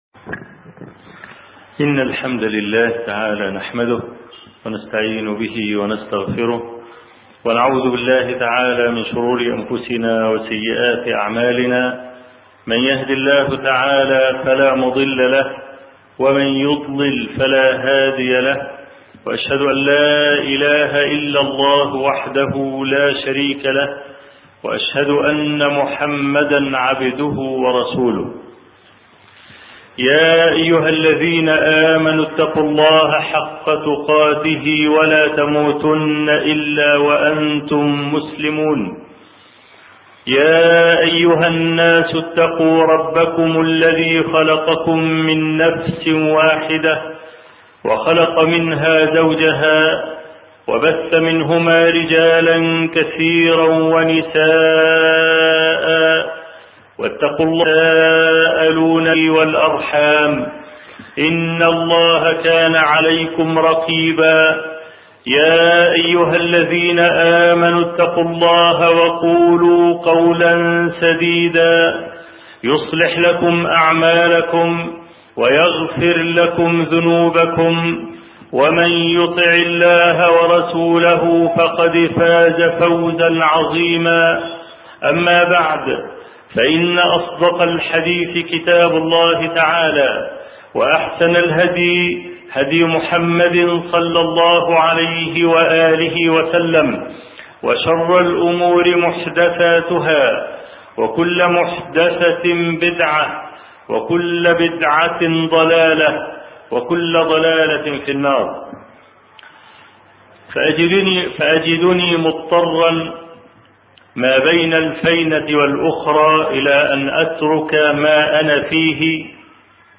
أرشيف الإسلام - ~ أرشيف صوتي لدروس وخطب ومحاضرات الشيخ أبو إسحاق الحويني